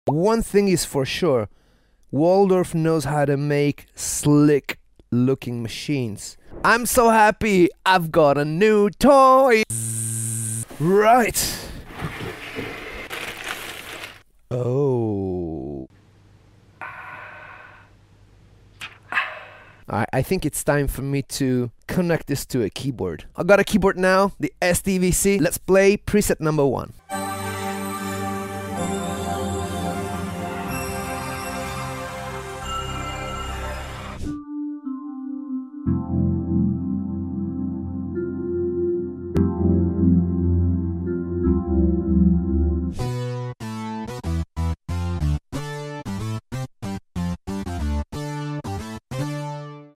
Such a good looking synthesizer